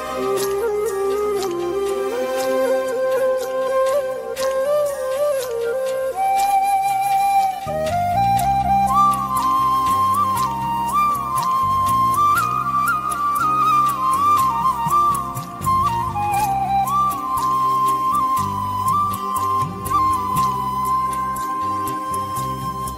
love song ringtone
romantic ringtone download